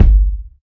Smooth Bottom End Steel Kick Drum Sample G Key 421.wav
Royality free kick tuned to the G note. Loudest frequency: 77Hz
smooth-bottom-end-steel-kick-drum-sample-g-key-421-mDK.mp3